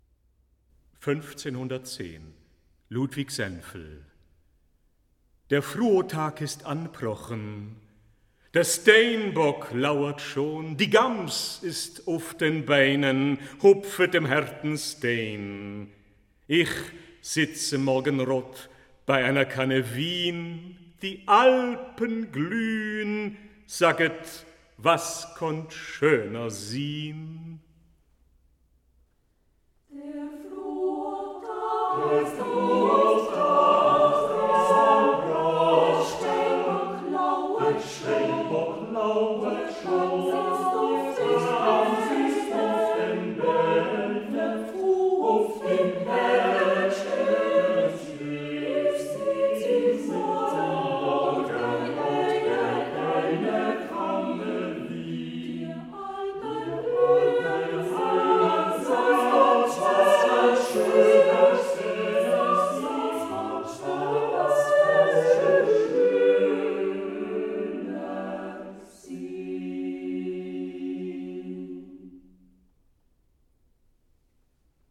(Choir/Chor)